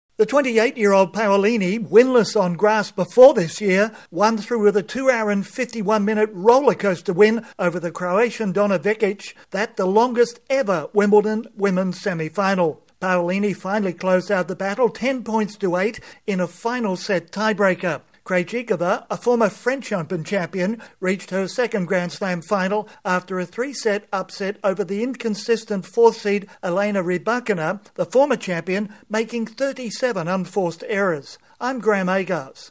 There will be a new name on the women's trophy at Wimbledon after Czech Barbora Krejcikova (kray cheek ova) and Italian Jasmine Paolina advanced to a surprise final. Correspondent